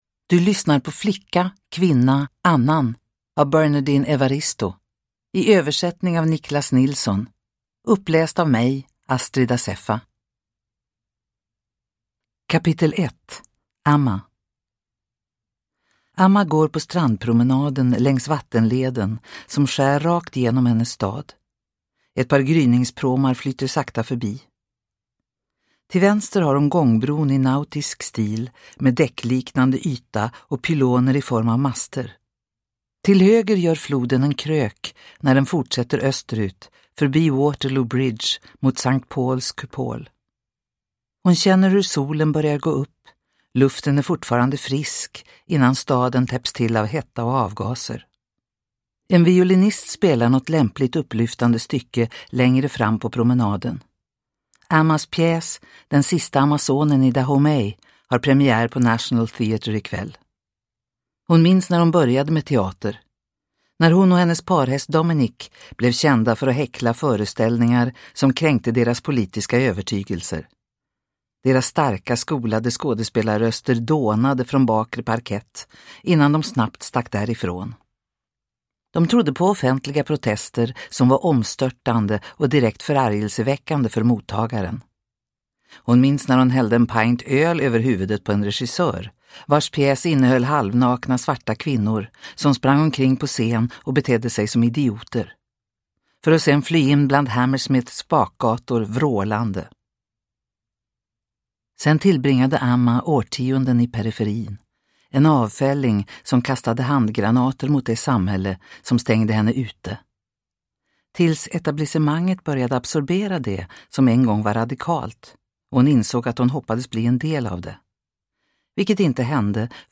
Flicka, kvinna, annan – Ljudbok – Laddas ner